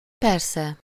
Ääntäminen
Synonyymit framgå Ääntäminen adverb: IPA: fɶrˈstɔs verb: IPA: fɶrˈstoːs Haettu sana löytyi näillä lähdekielillä: ruotsi Käännös Ääninäyte 1. természetesen 2. persze Förstås on sanan förstå taipunut muoto.